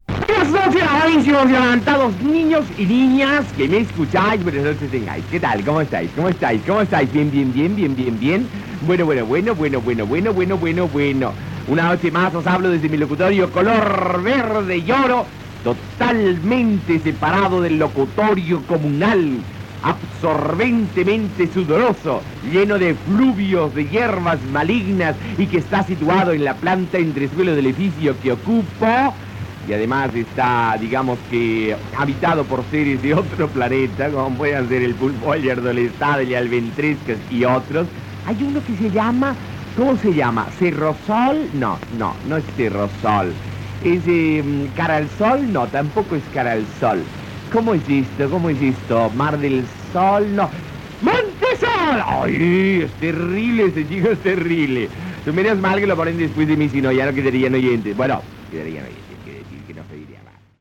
"Ecos mundanos", salutació inicial i comentari sobre els inegrants de l'equip del programa "Al mil por mil"
FM